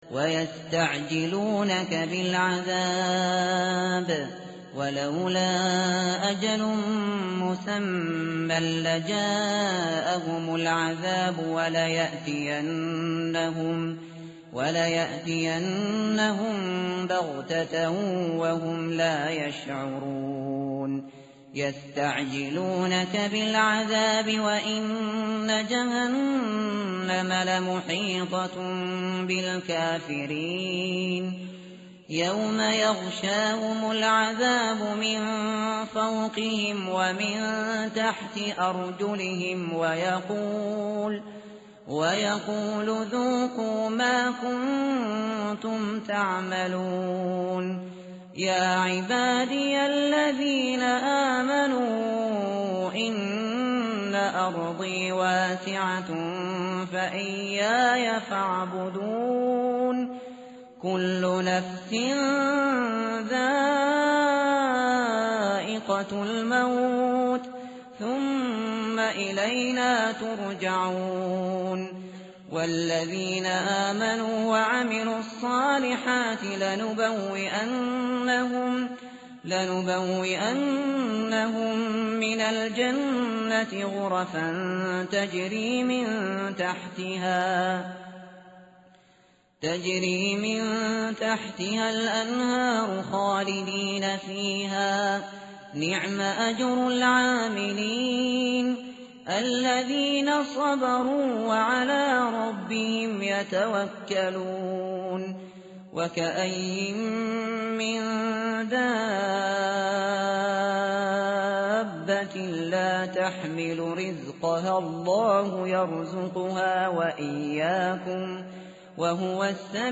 متن قرآن همراه باتلاوت قرآن و ترجمه
tartil_shateri_page_403.mp3